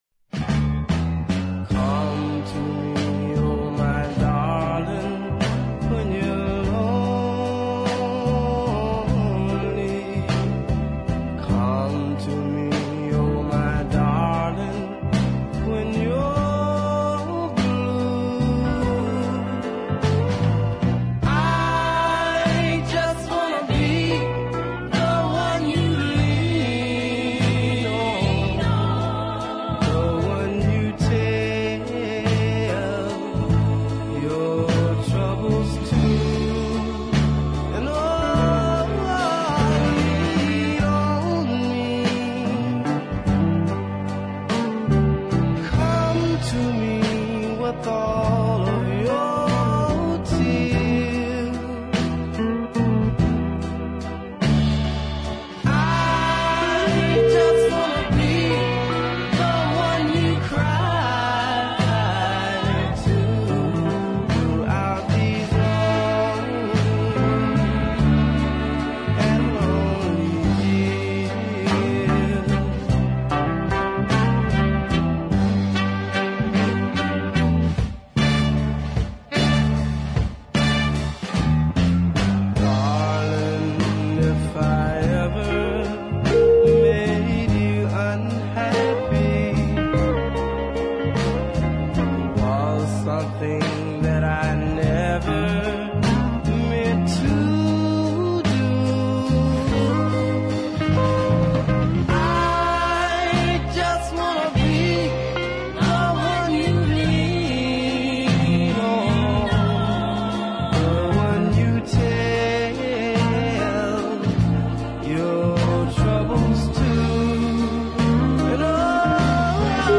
Muscle Shoals
The subtle key modulation is beautifully done.